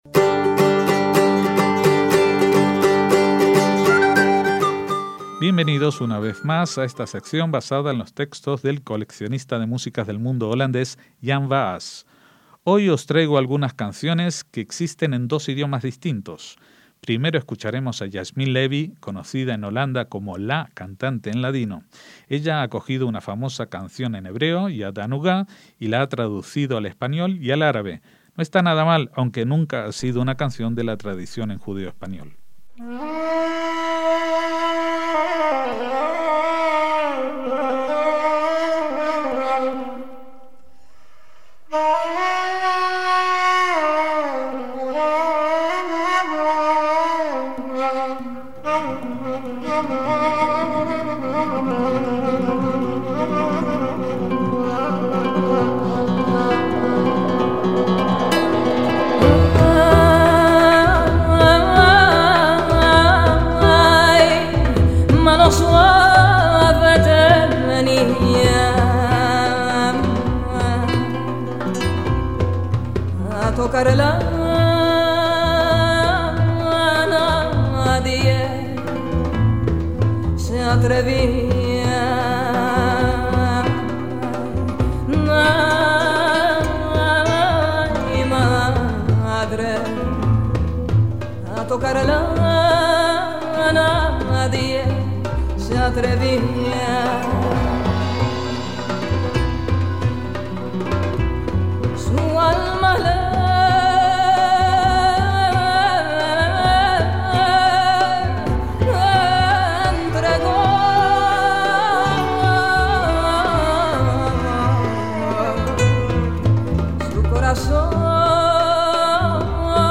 música judía